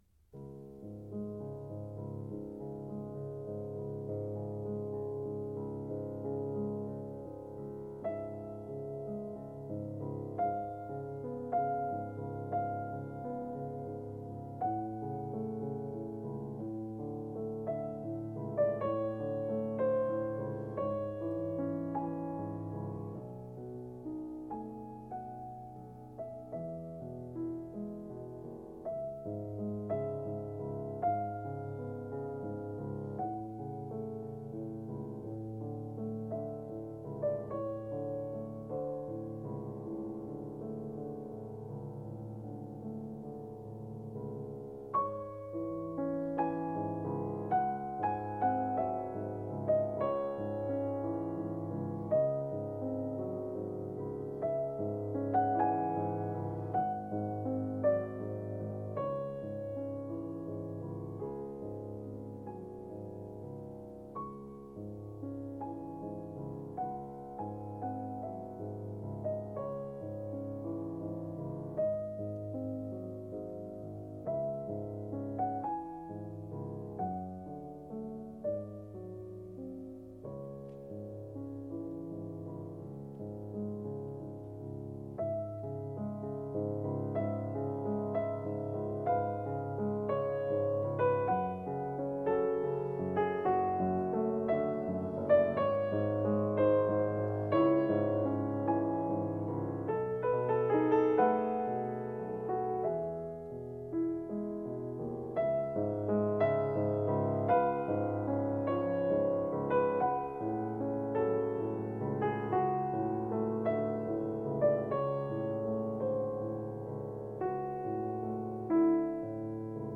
升C小调，OP27.1，作于1835年，献给达勃尼伯爵夫人，极慢板，三段形式：柔声、转快、悲痛主题，转为明朗。